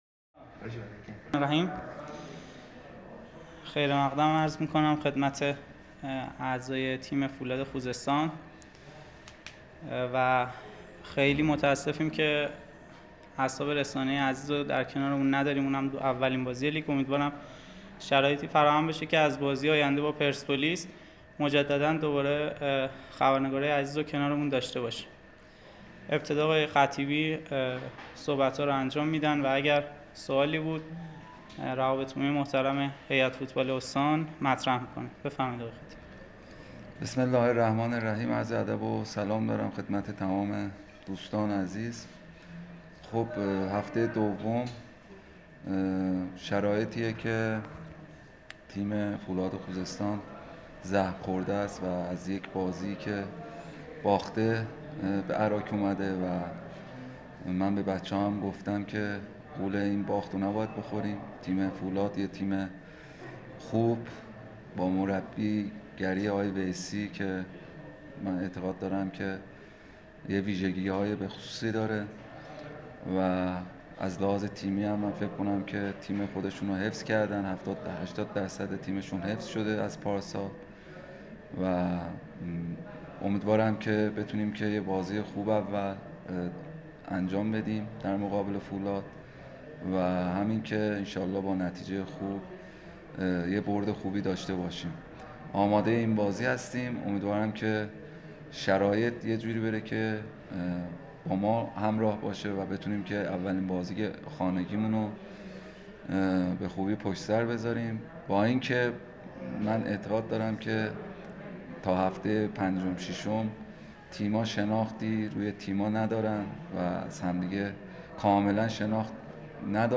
نشست خبری سرمربی آلومینیوم اراک
نشست خبری رسول خطیبی، سرمربی تیم آلومینیوم اراک پیش از بازی مقابل فولاد برگزار شد.